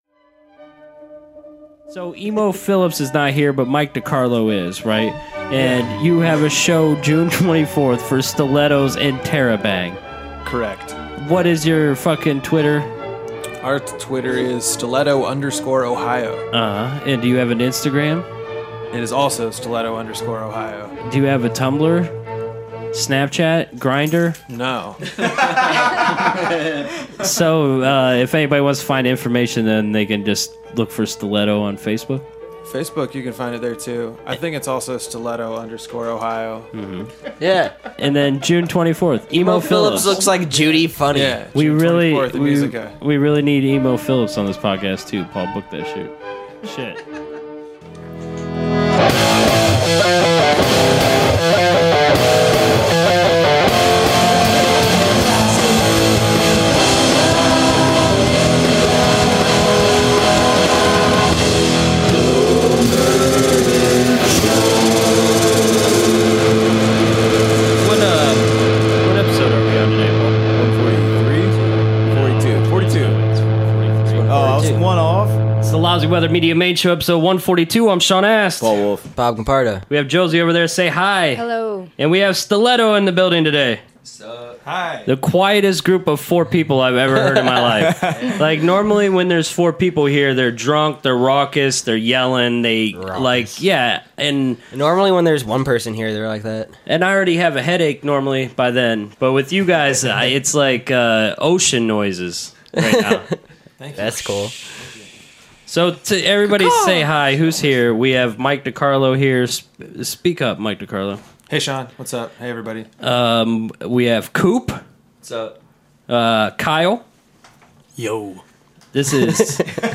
in studio
to premiere a few new songs from their upcoming E.P.